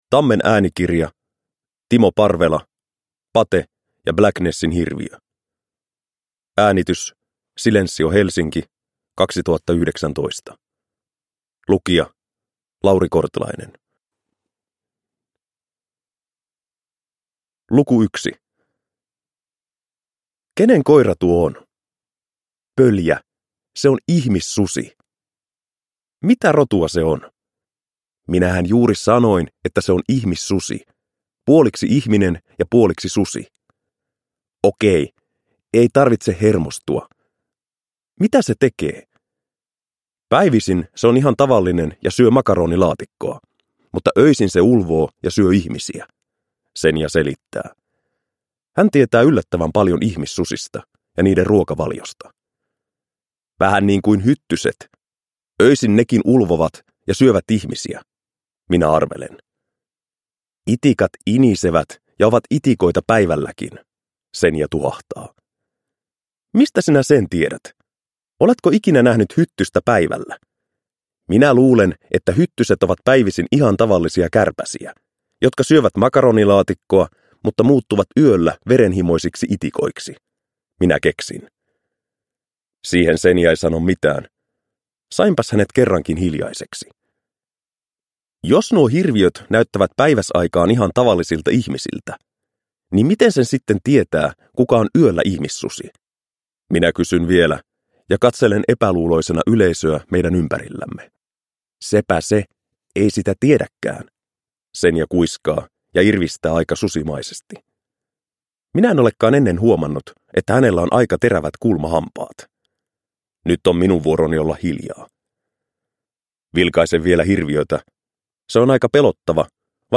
Pate ja Blacknessin hirviö – Ljudbok – Laddas ner